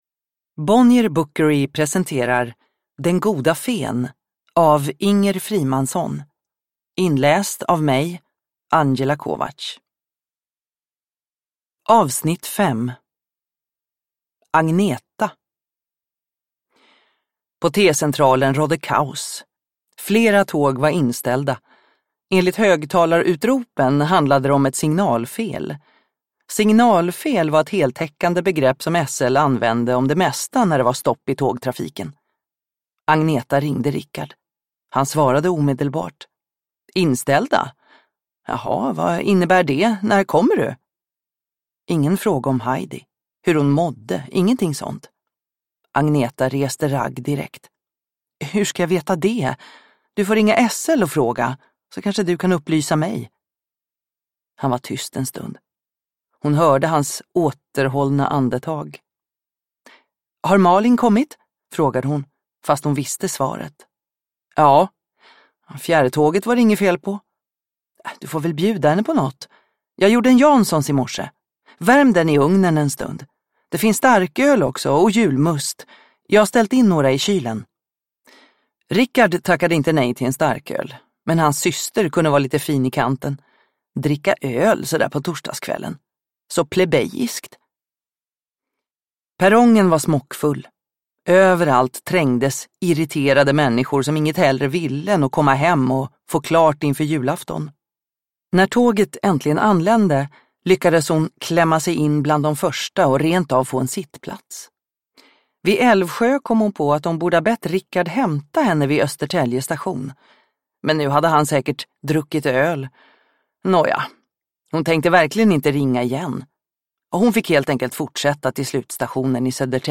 Den goda fen E5 (ljudbok) av Inger Frimansson